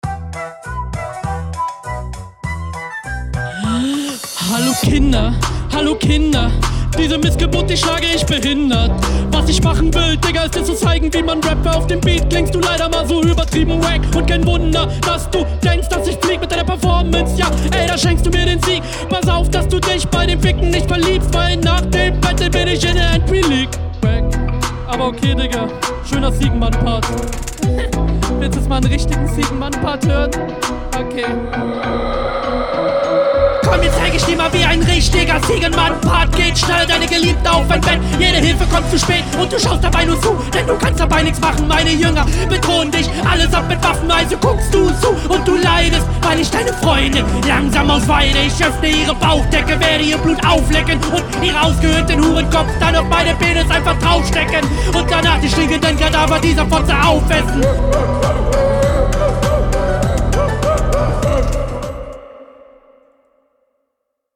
Flow: wesentlich besser.